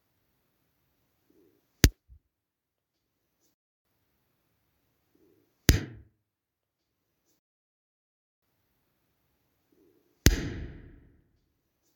In unserem Audio-Beispiel hören Sie mich dreimal klatschen.
1. Das erste Klatschen haben wir im Freifeldraum aufgenommen. Es klingt kurz und „trocken“.
2. Danach hören Sie dasselbe Klatschen mit dem Nachhall eines kleinen Raums. Durch den Nachhall klingt das Klatschen länger.
3. Als Drittes hören Sie das Klatschen im noch größeren Raum. Die „Nachhallfahne“ ist hier noch länger.
schalltoter-raum-klatschen.mp3